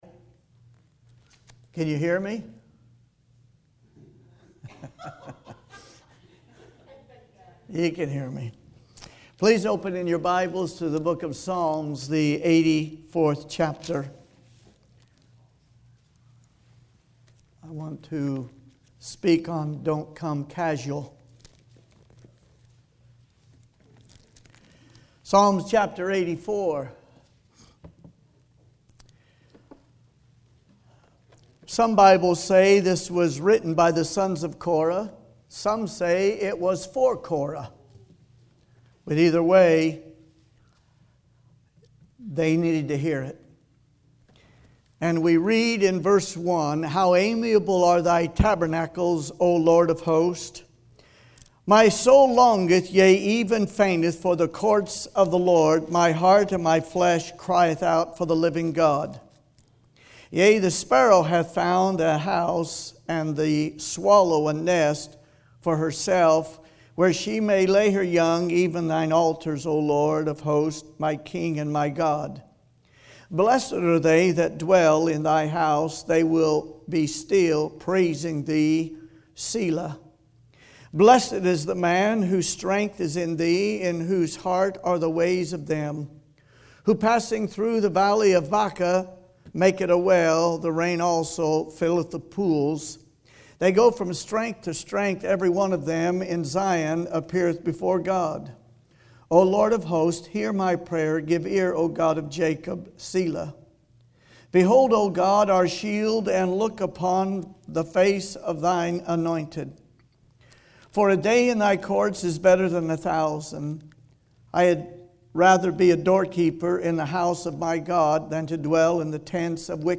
Evening Sermons